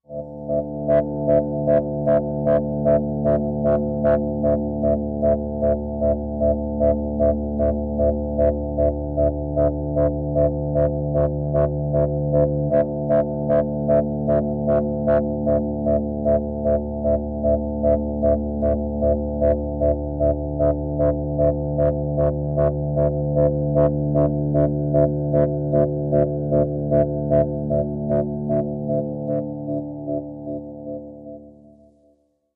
Pulsing Drips Moving Electro Pulses Dripping arcs